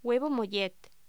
Locución: Huevo mollet